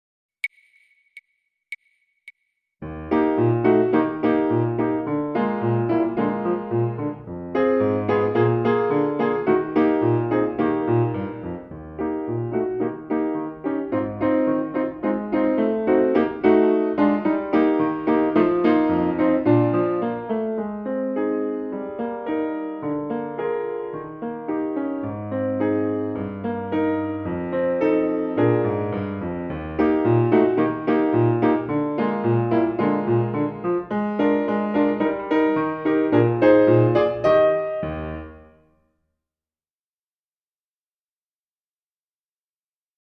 Gattung: Tuba & Klavier (inkl. CD)